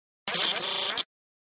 Звуки глюков
На этой странице собрана коллекция звуков цифровых глюков, сбоев и помех.